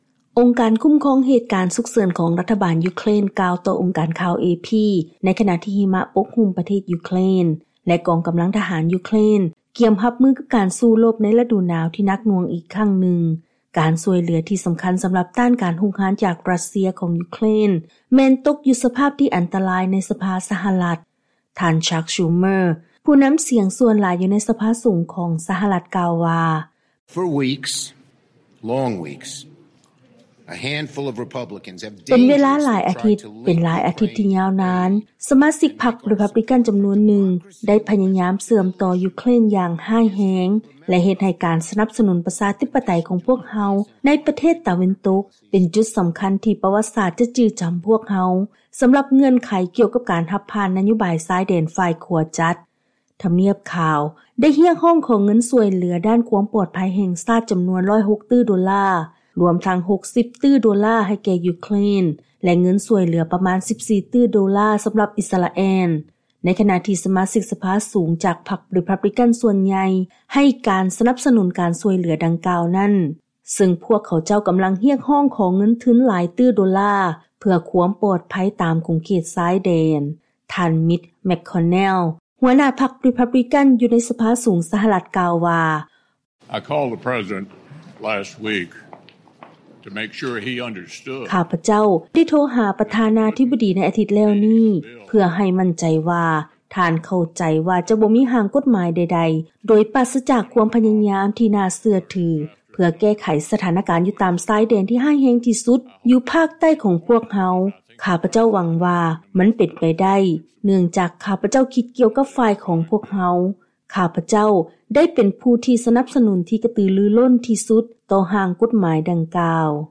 ເຊີນຟັງລາຍງານກ່ຽວກັບ ບັນດາສະມາຊິກສະພາ ສະຫະລັດ ມີເວລາສັ້ນລົງໃນການເຈລະຈາການຊ່ວຍເຫລືອແກ່ ຢູເຄຣນ
ທ່ານກ່າວຜ່ານສໄກປ໌ ວ່າ: